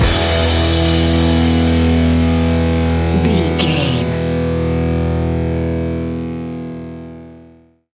Ionian/Major
hard rock
heavy rock
distortion
instrumentals